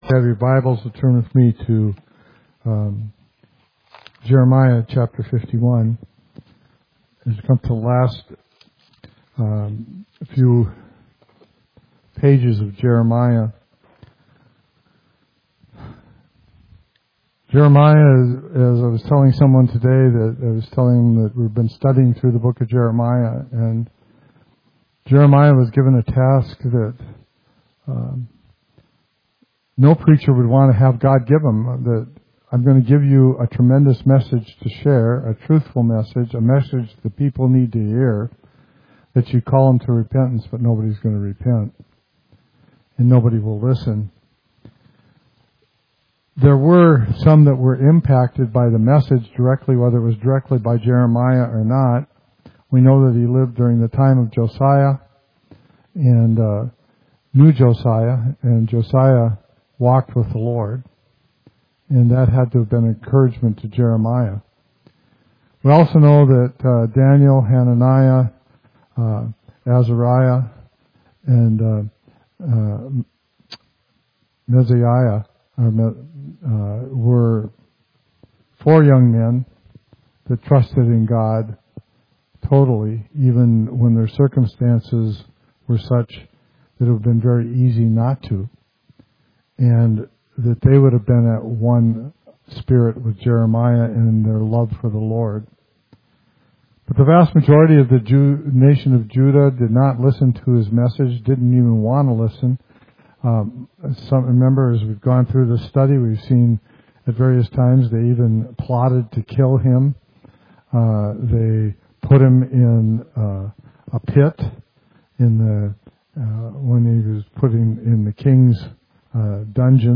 In Jeremiah 51:10–17, God’s righteousness is made known as He brings justice upon Babylon. This sermon explores how the Lord exposes false gods and demonstrates His power over all creation.